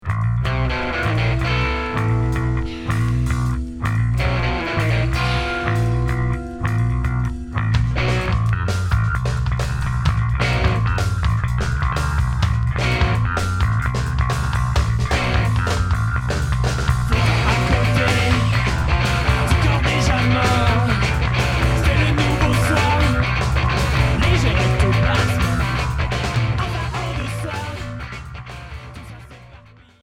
Emo core